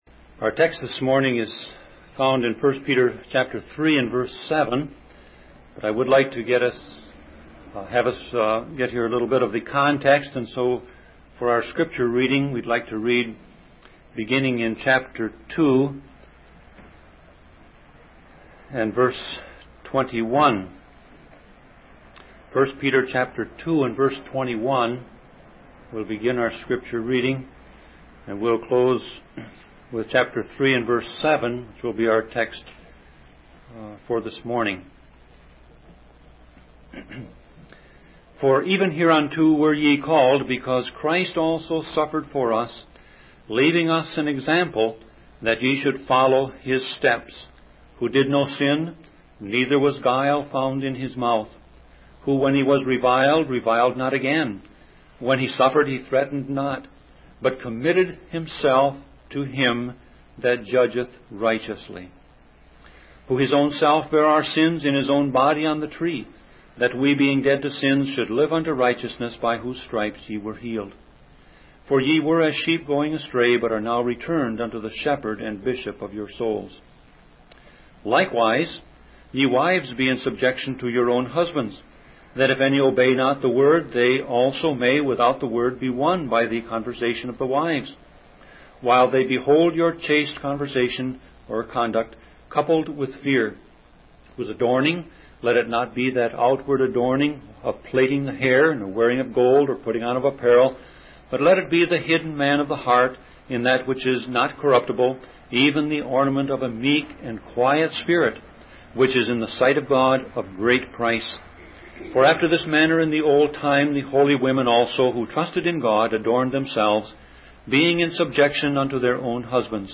Sermon Audio Passage: 1 Peter 3:7 Service Type